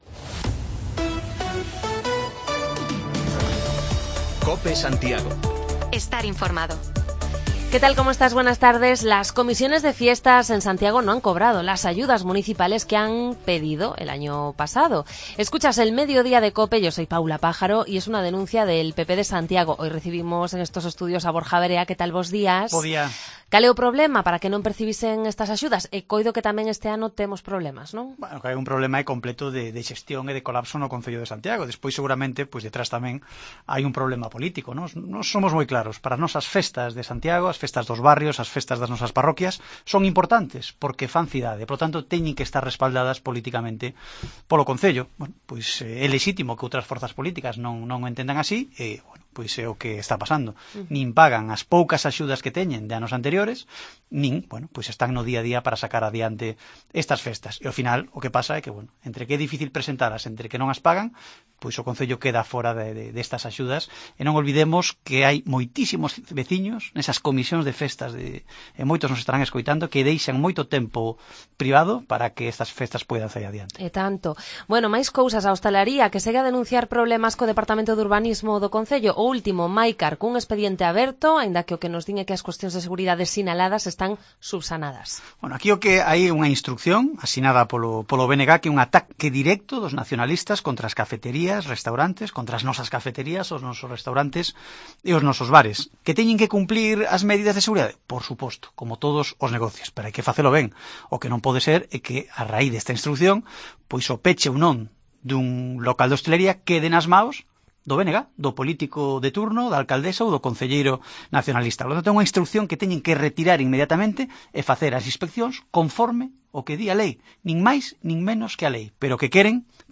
Repasamos la actualidad municipal con el portavoz del Pp en Santiago, Borja Verea.